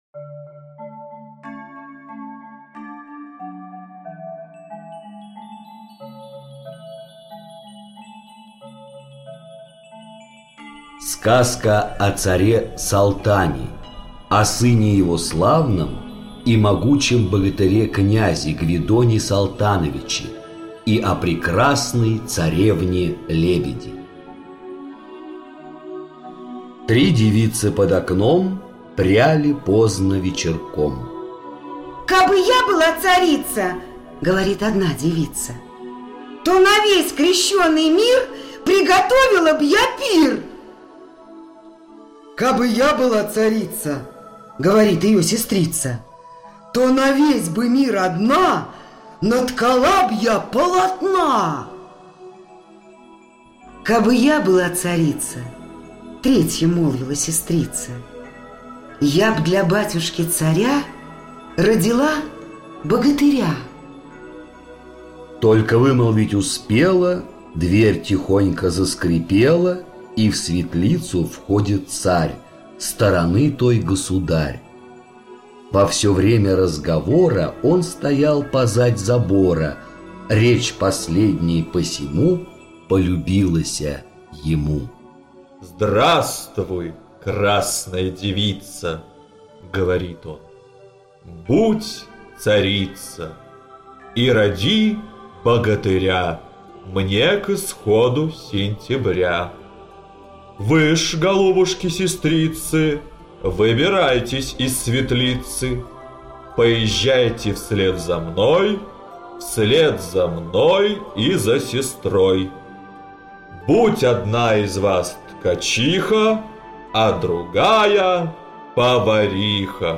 Аудиокнига Сказка о царе Салтане | Библиотека аудиокниг